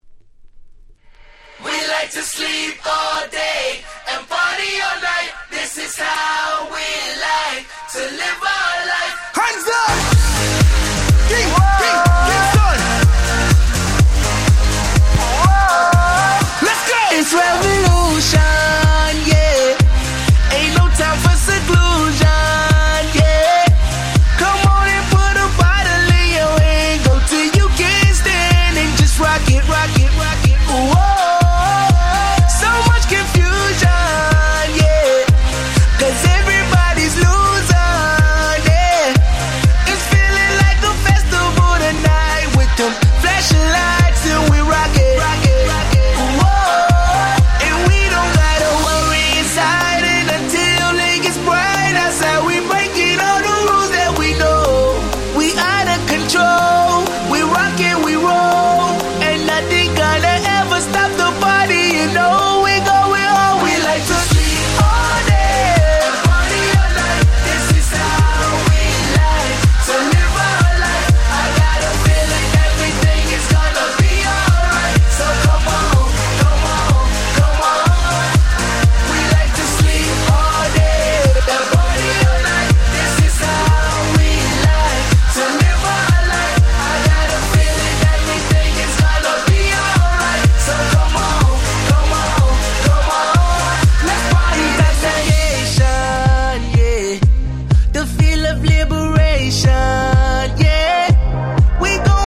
10' Smash Hit R&B !!